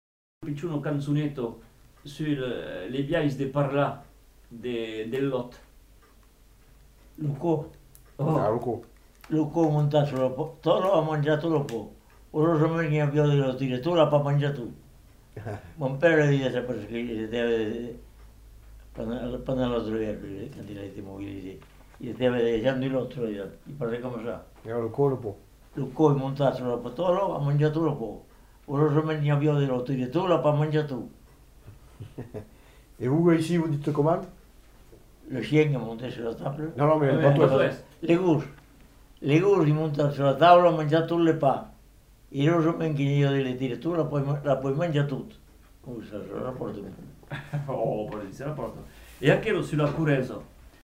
Lieu : Caraman
Genre : forme brève
Effectif : 1
Type de voix : voix d'homme
Production du son : récité